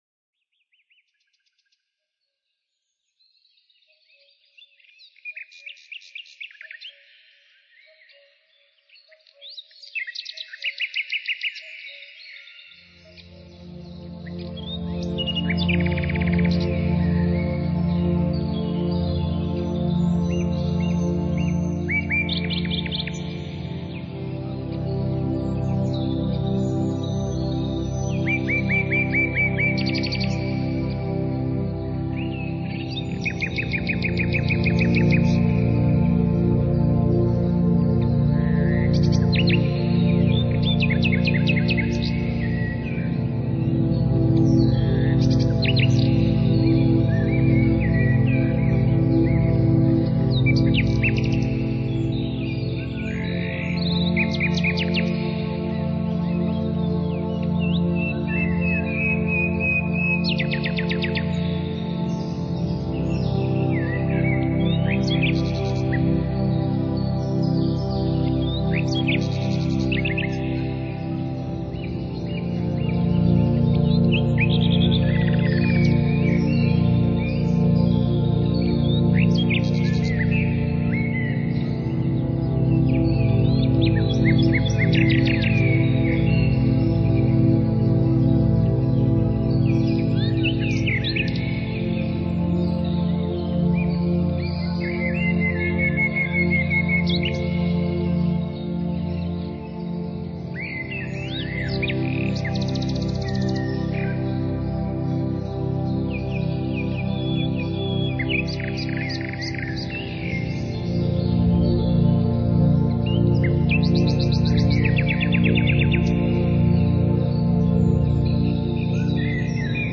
LoFi Mp3
nature sounds and atmospheric sounds, perfect for relaxation